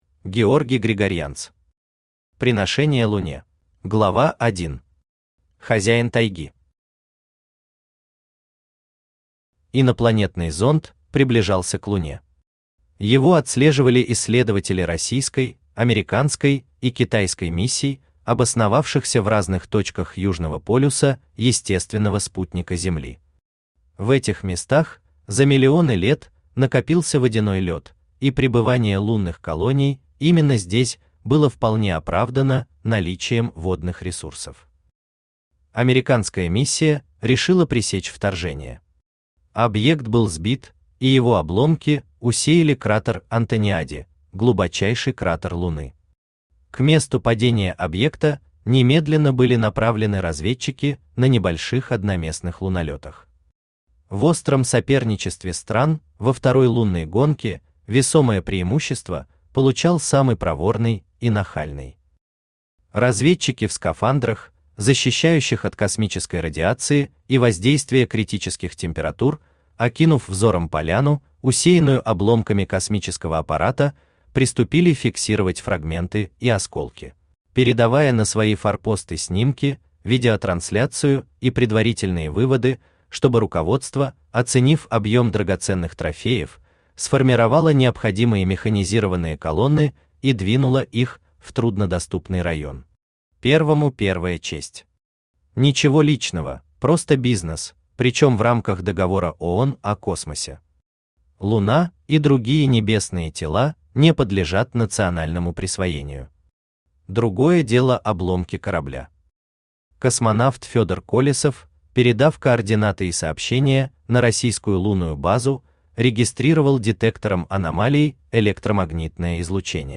Читает: Авточтец ЛитРес
Аудиокнига «Приношение Луне».